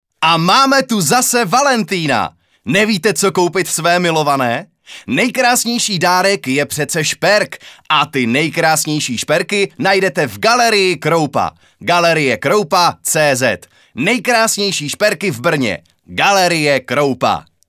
ukázka reklama:
reklama.mp3